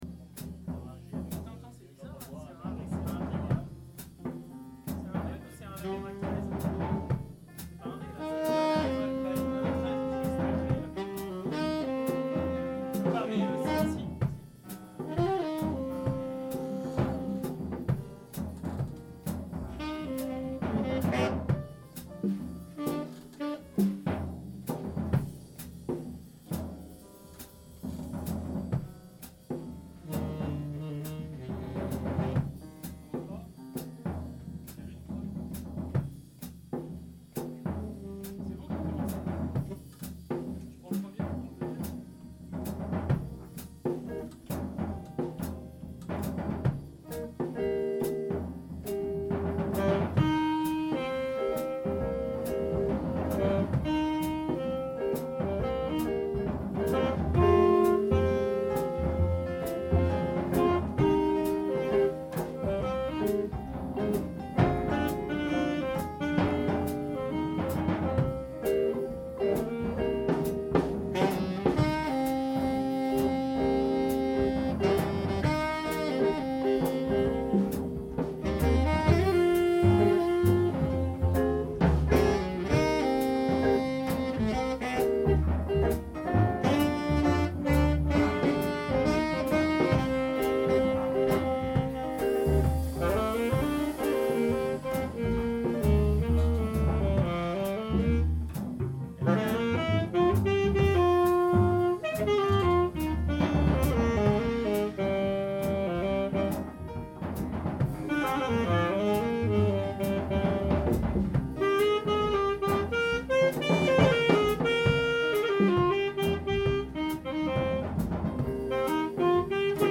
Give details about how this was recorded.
Deux nouveaux morceaux joués en répétition:(cliquer)